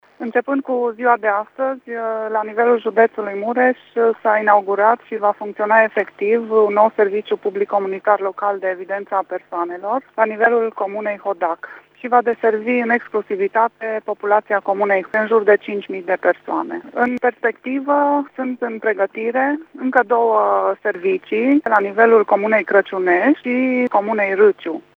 Directorul executiv al Direcției Județene de Evidența Persoanelor Mureș, Codruţa Sava, a anunțat că și în comunele mureșene Crăciunești și Râciu se vor înființa asemenea servicii: